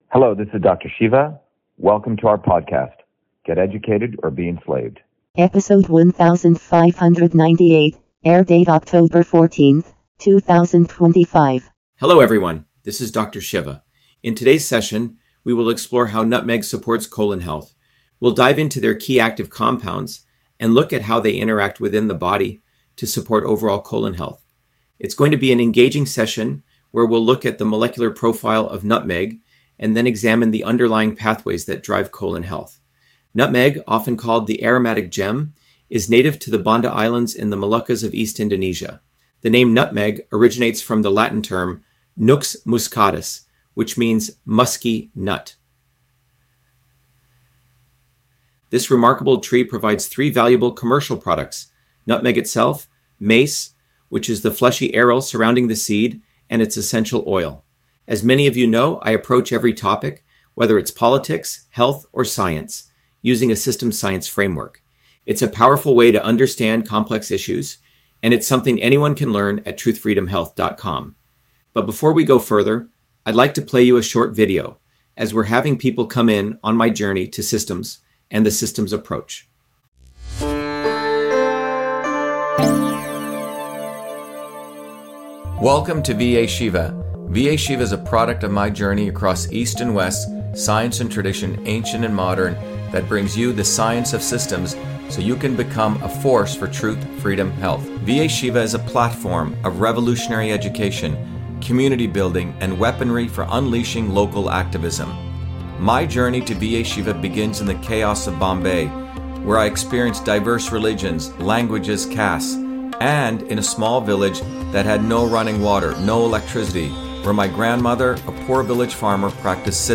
In this interview, Dr.SHIVA Ayyadurai, MIT PhD, Inventor of Email, Scientist, Engineer and Candidate for President, Talks about Nutmeg on Colon Health: A Whole Systems Approach